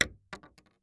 Bullet Shell Sounds
shotgun_wood_7.ogg